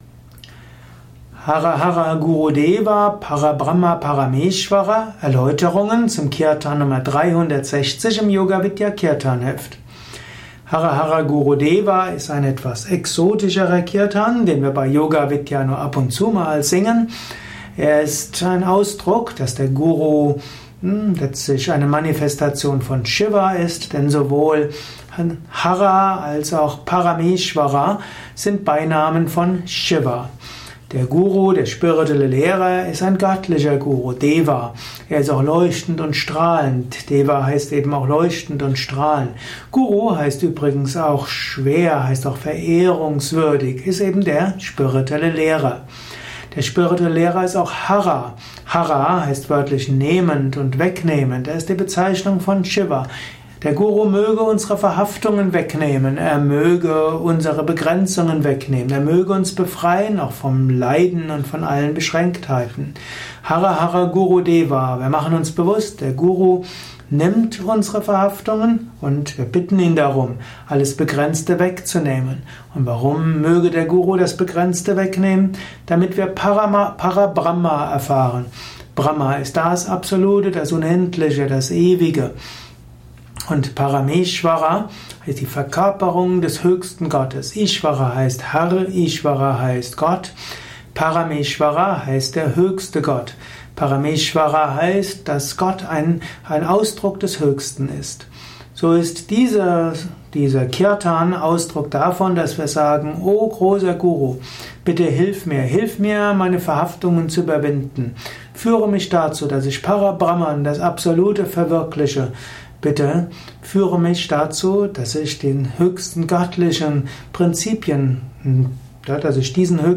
360 im Yoga Vidya Kirtanheft , Tonspur eines Kirtan